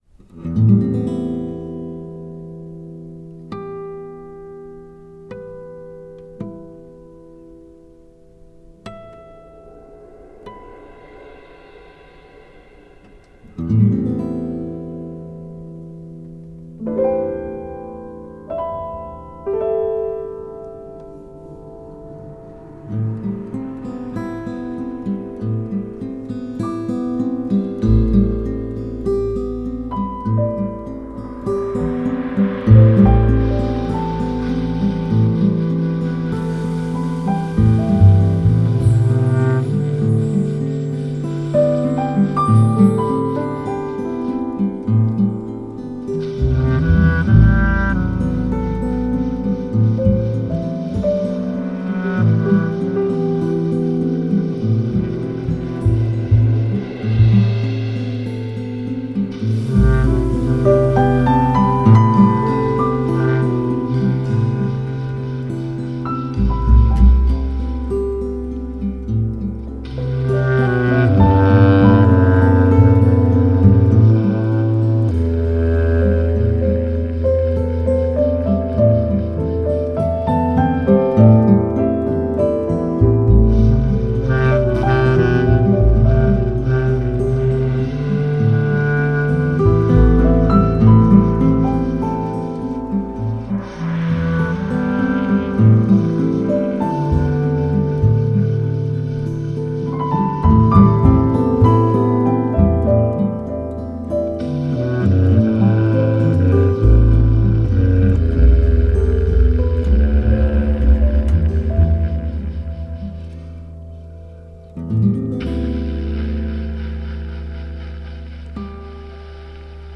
bass clarinet
kantele
guitar
piano
drums
Film and Score live